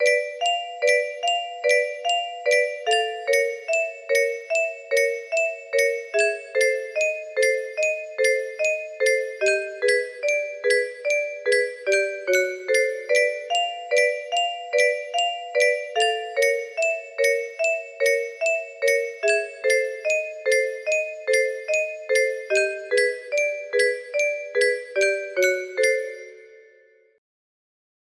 ml1 music box melody